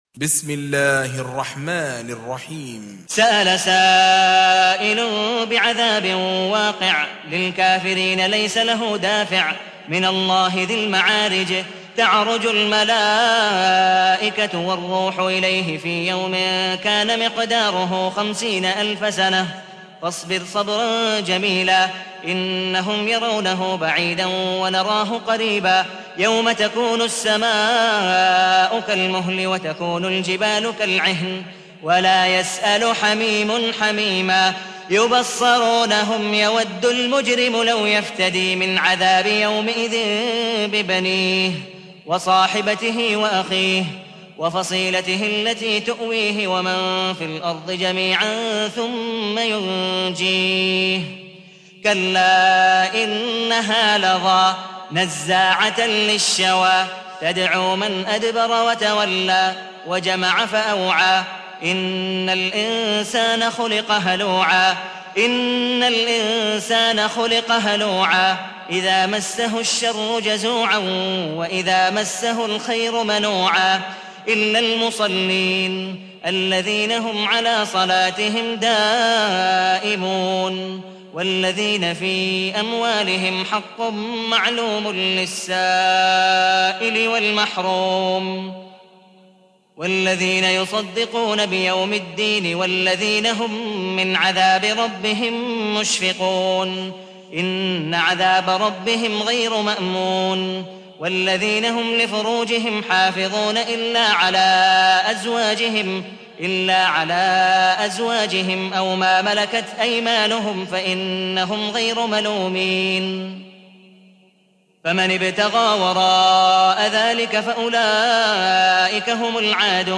تحميل : 70. سورة المعارج / القارئ عبد الودود مقبول حنيف / القرآن الكريم / موقع يا حسين